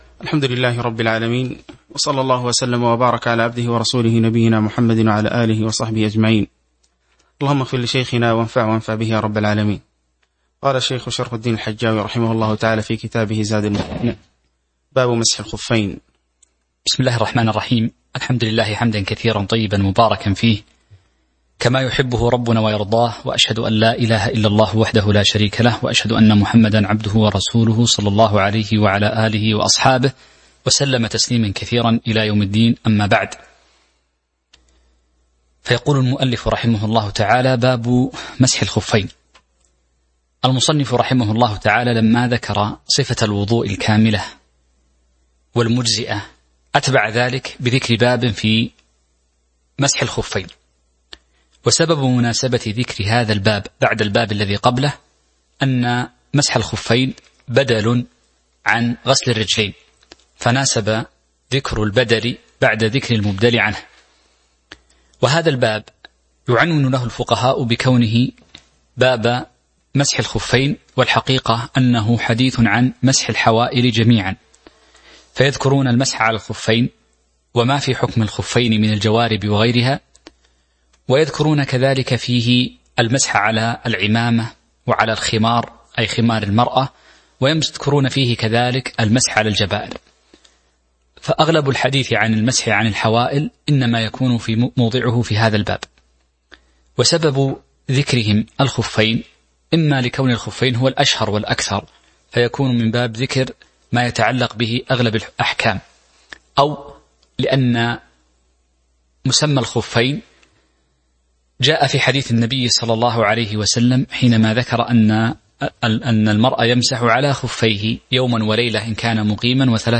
تاريخ النشر ١٨ ذو الحجة ١٤٤٢ هـ المكان: المسجد النبوي الشيخ